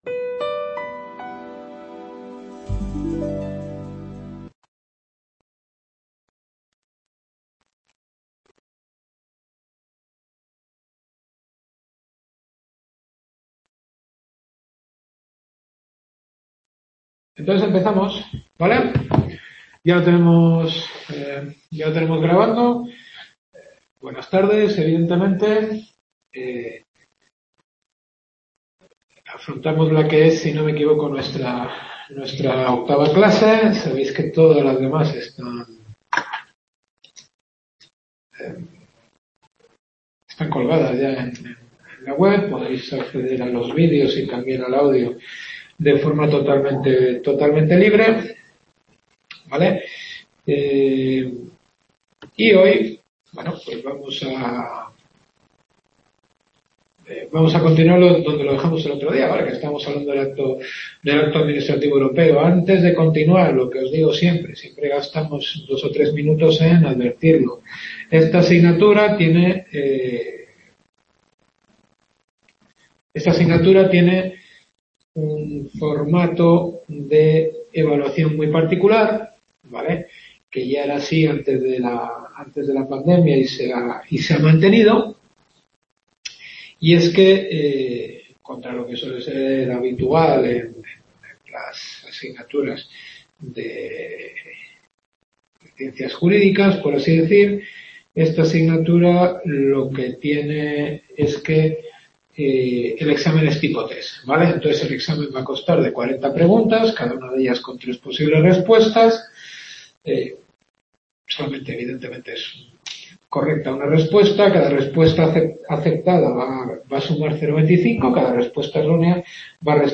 Derecho Administrativo Europeo. Octava clase.